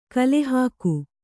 ♪ kalehāku